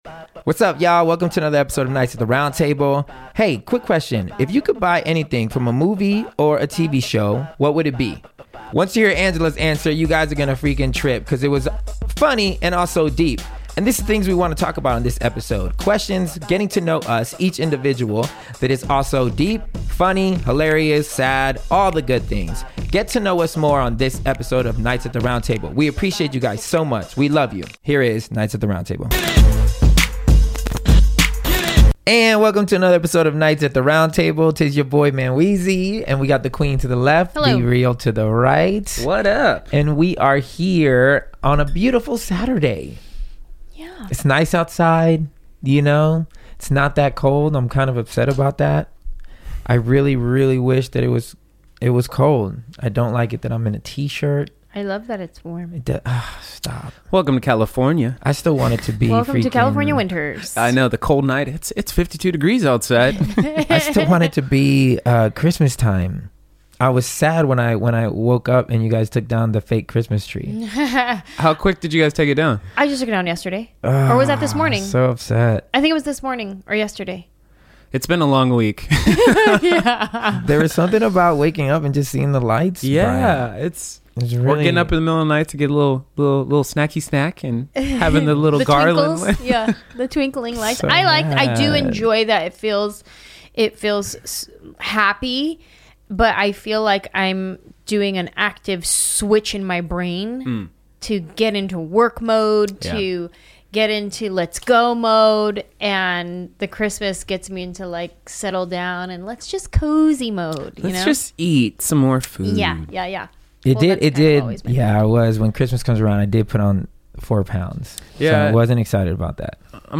Low key and light hearted, its always a good thing to not take yourself so serious.